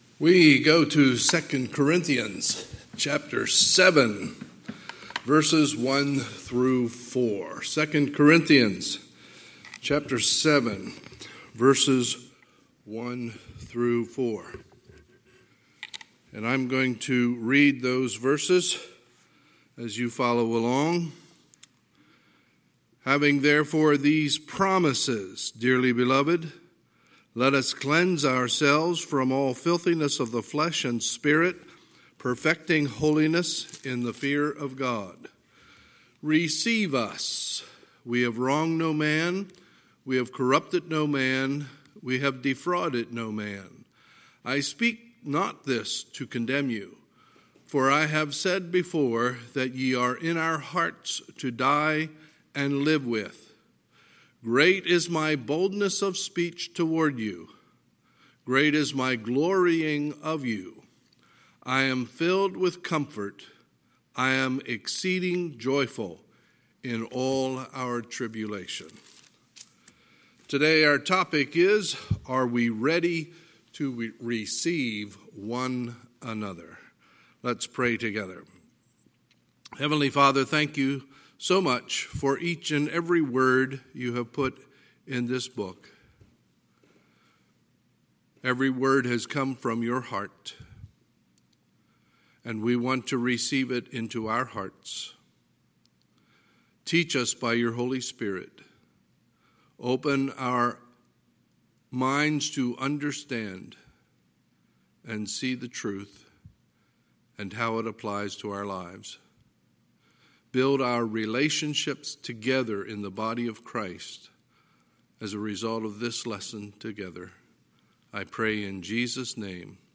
Sunday, June 7, 2020 – Sunday Morning Service